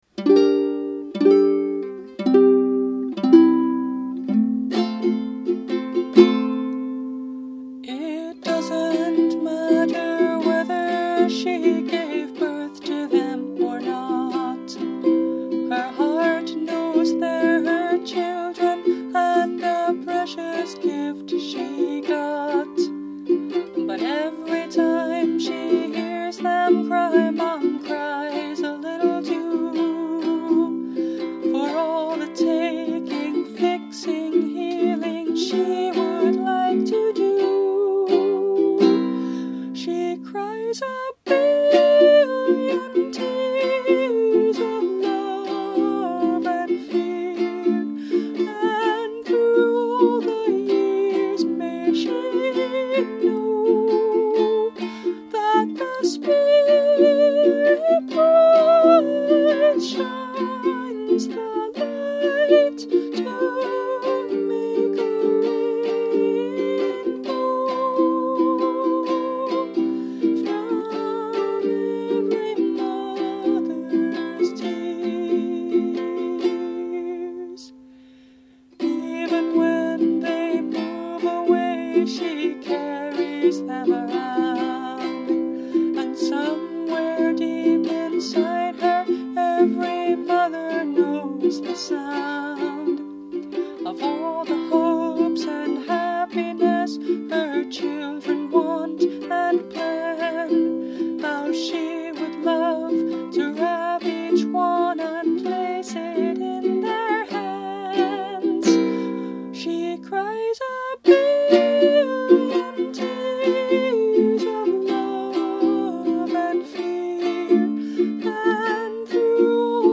Instrument: Brio – Red Cedar Concert Ukulele